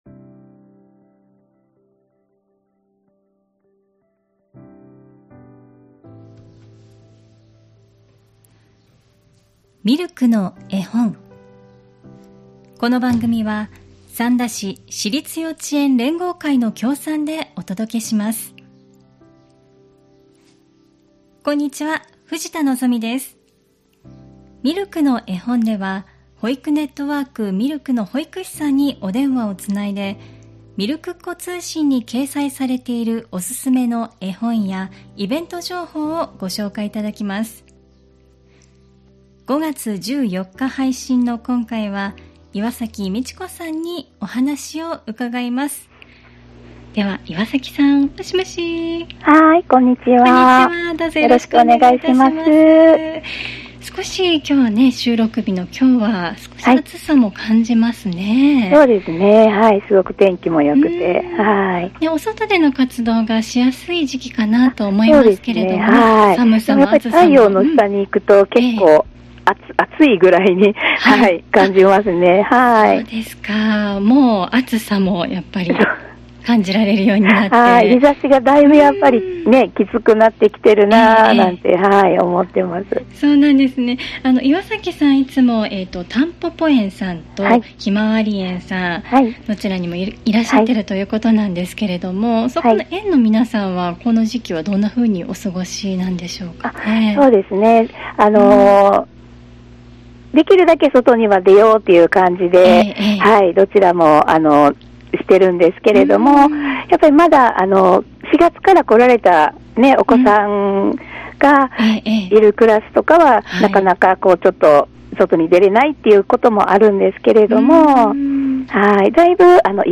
保育ネットワーク・ミルクの保育士さんにお電話をつないで、みるくっ子通信に掲載されているおすすめの絵本やイベント・施設情報などお聞きします。